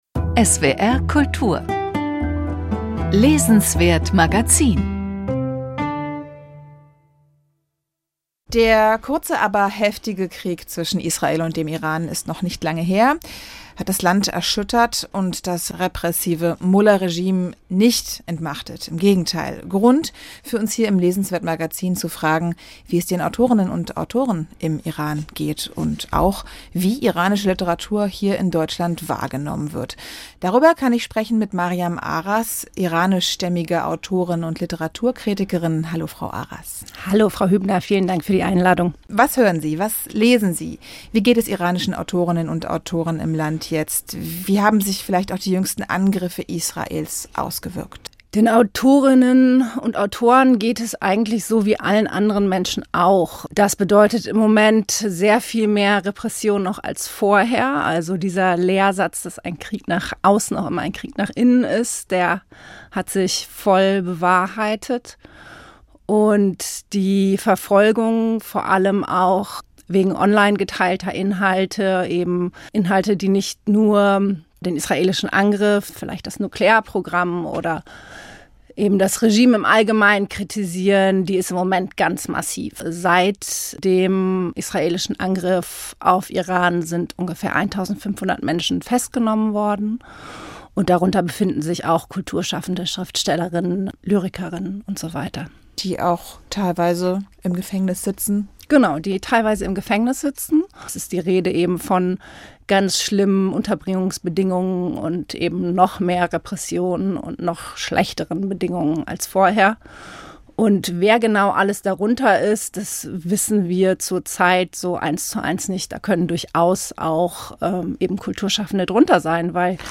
Einblick in die aktuelle Situation im Iran | Gespräch